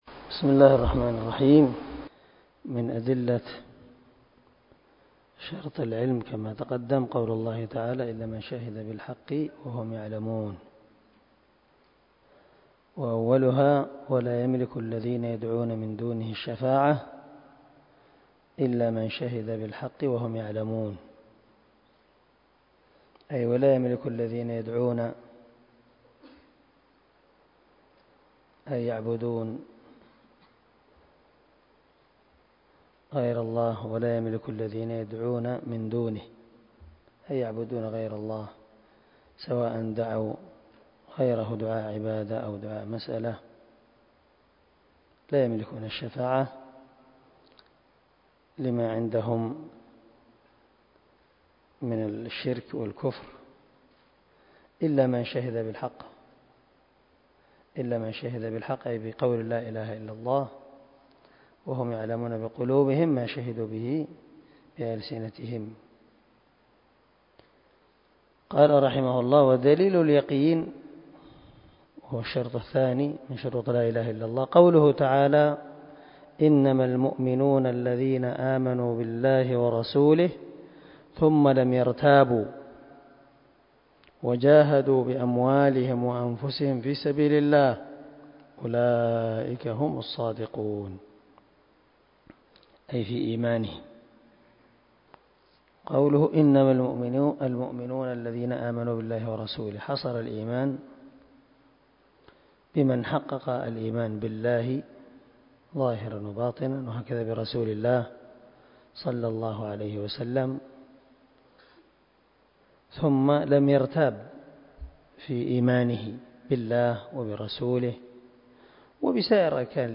🔊الدرس 5 أدلة شرط اليقين( من شرح الواجبات المتحتمات)